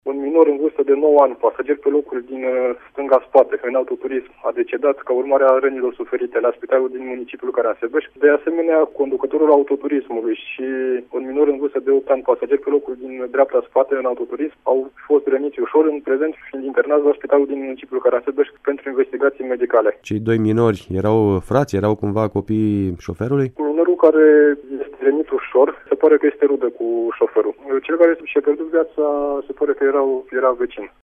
reportaj